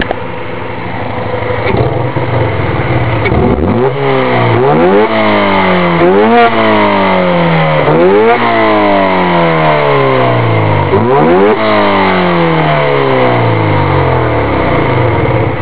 De dedans...le moteur, cela donne ça:
moteur.wav